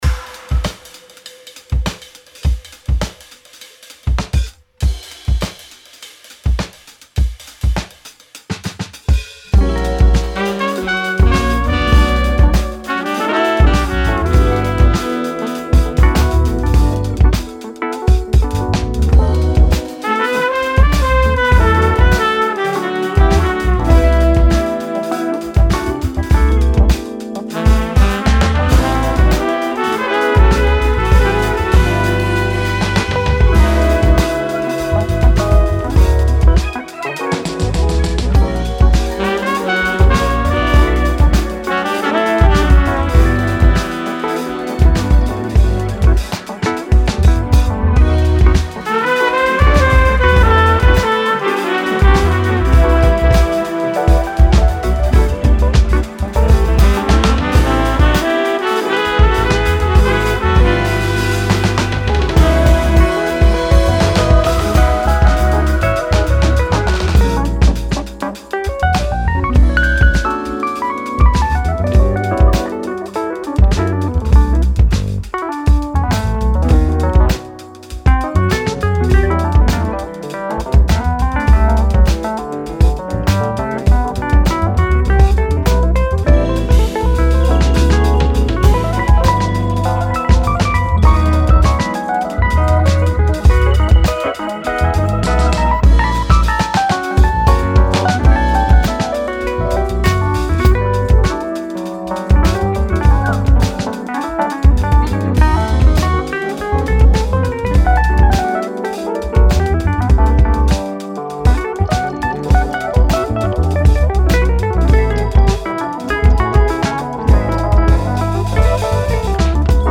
Du jazz britannique charmeur et groovy
le claviériste